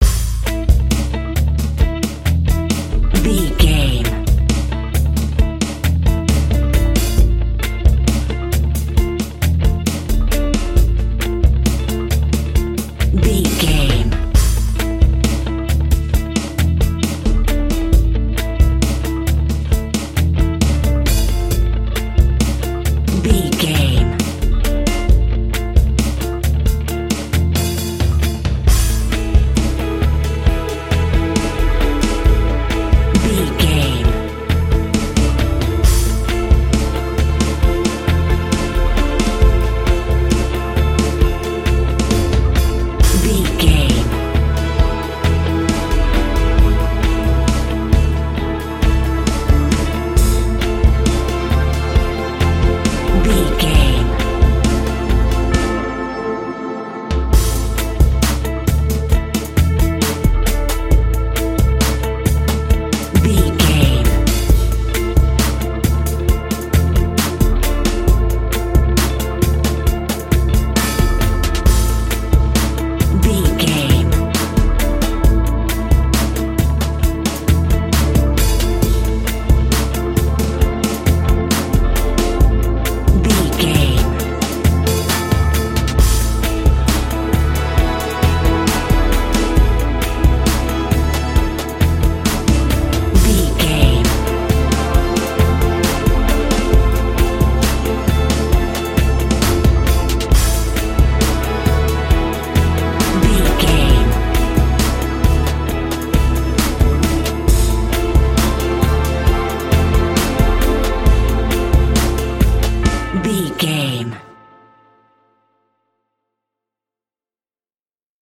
Aeolian/Minor
drums
electric guitar
bass guitar